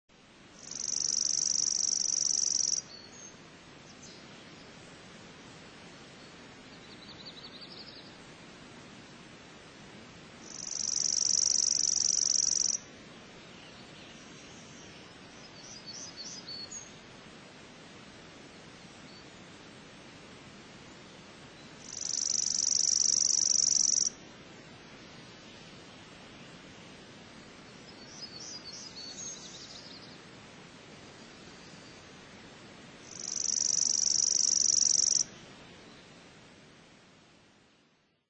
Bird Photography Weekly - Chipping Sparrow
These sparrows are known for their neat trilling song.